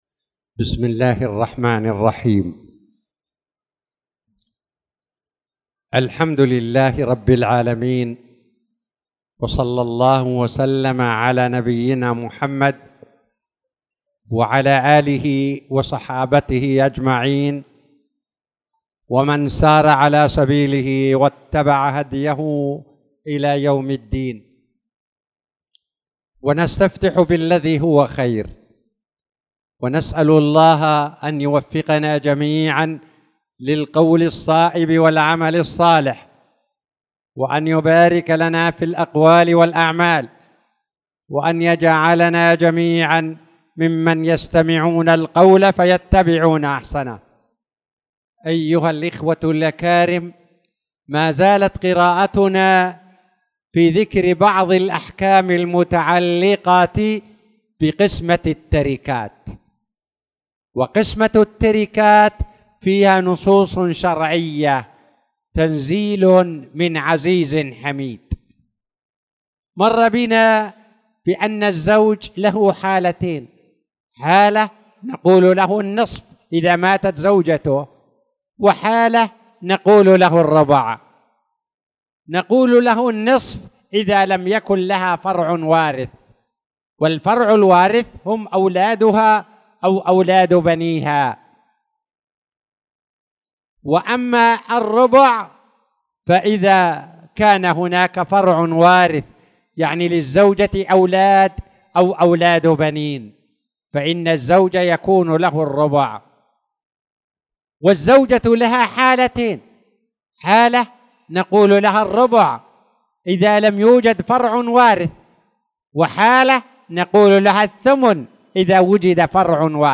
تاريخ النشر ١٢ شوال ١٤٣٧ هـ المكان: المسجد الحرام الشيخ